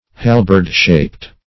Halberd-shaped \Hal"berd-shaped`\ (-sh[=a]pt`), a.
halberd-shaped.mp3